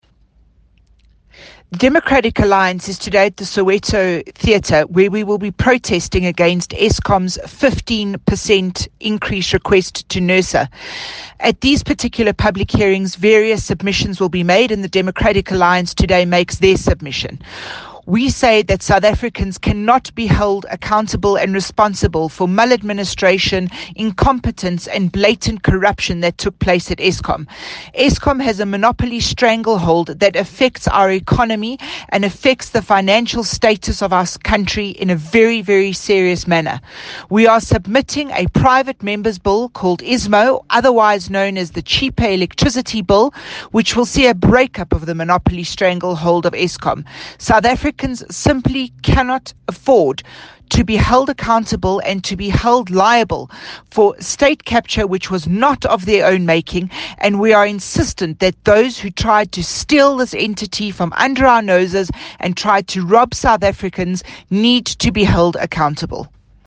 Note to Editors: Please find attached a soundbite in
English by Natasha Mazzone, the DA Team One SA Spokesperson on State Capture.